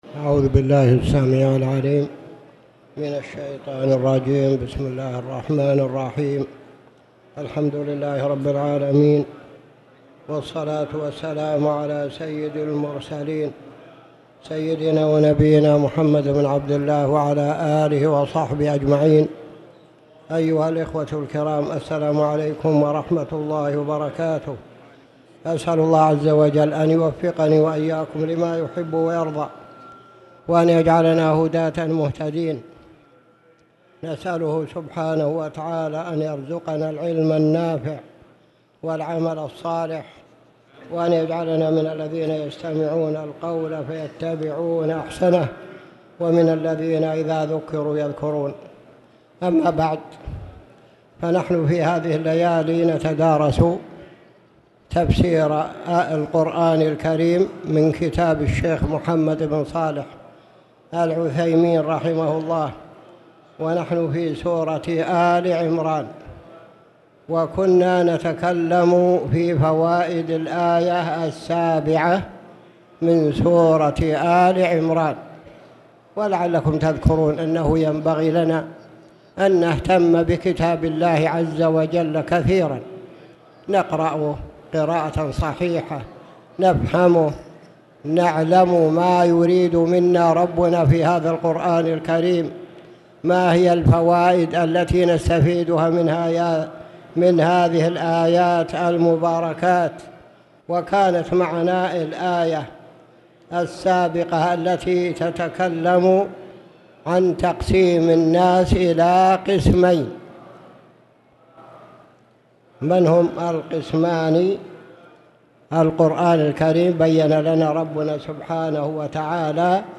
تاريخ النشر ٩ ربيع الثاني ١٤٣٨ هـ المكان: المسجد الحرام الشيخ